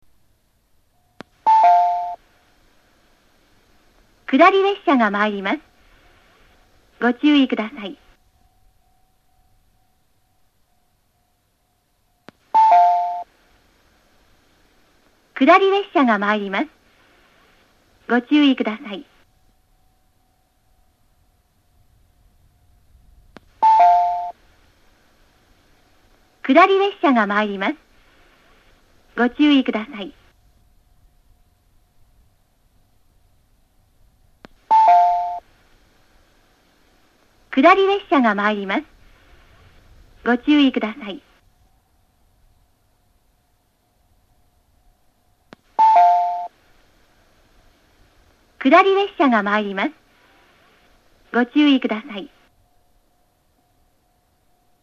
旧放送◆
収録当時のスピーカーは前沢と同じくユニペックス小型でした（同社製クリアホーンスピーカー）。
接近放送
女性による接近放送です。